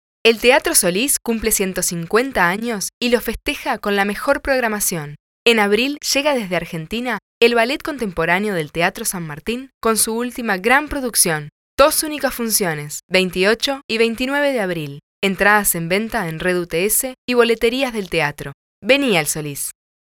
Spanish/English/Portuguese EspaĂ±ol rioplatense, espaĂ±ol uruguayo, espaĂ±ol neutro
Sprechprobe: Industrie (Muttersprache):
Publicidad institucional_1.mp3